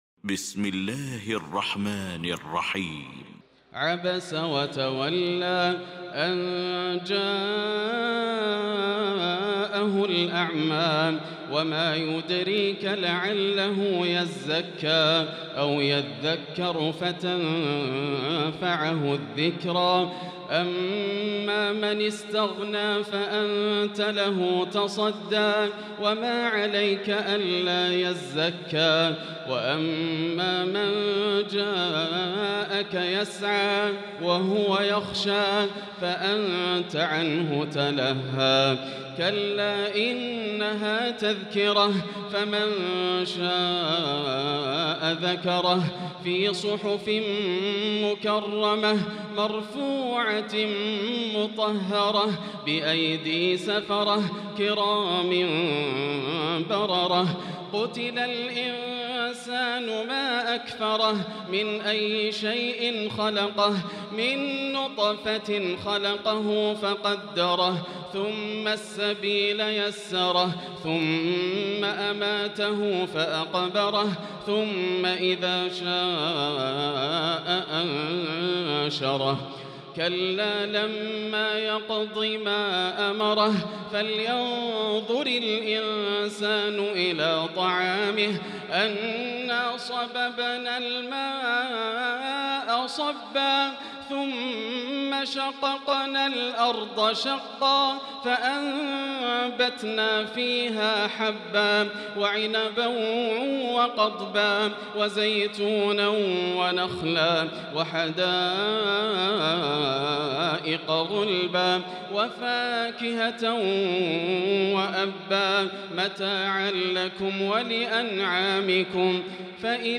المكان: المسجد الحرام الشيخ: فضيلة الشيخ ياسر الدوسري فضيلة الشيخ ياسر الدوسري عبس